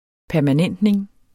Udtale [ pæɐ̯maˈnεnˀdneŋ ]